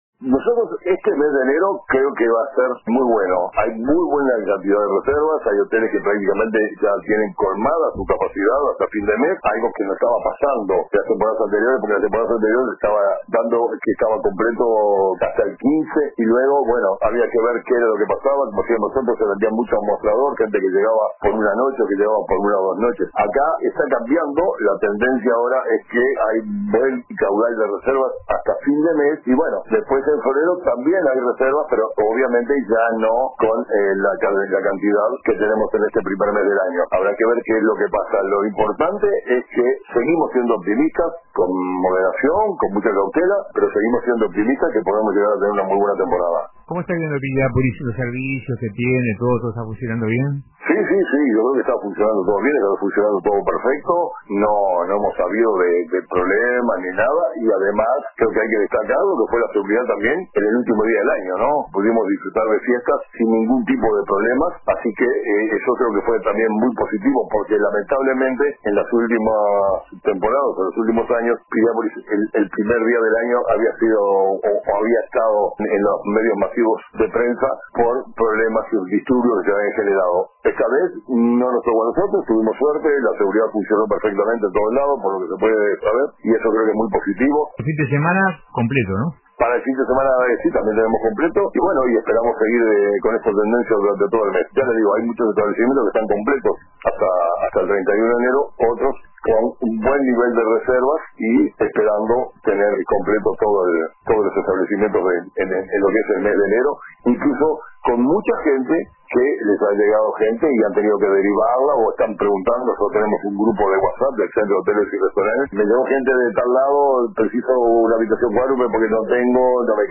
destacó en declaraciones a RADIO RBC que la temporada turística ha comenzado de manera muy positiva.